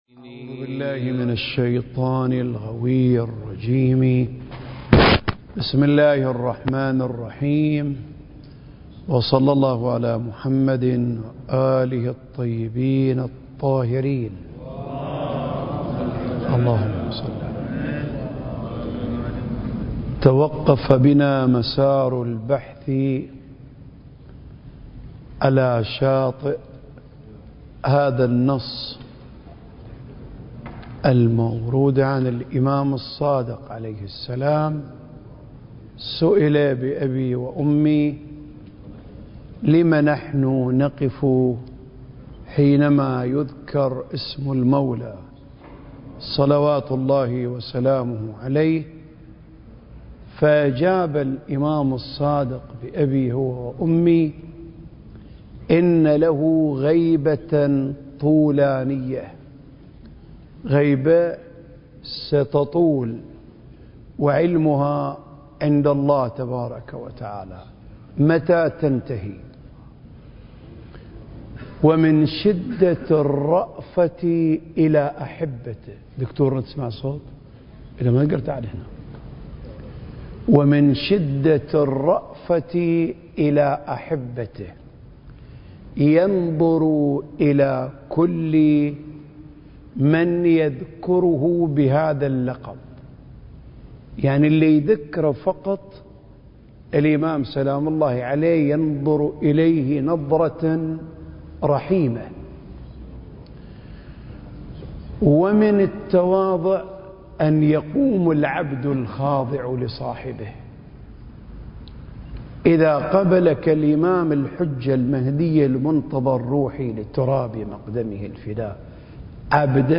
سلسلة محاضرات: آفاق المعرفة المهدوية (6) المكان: الأوقاف الجعفرية بالشارقة التاريخ: 2023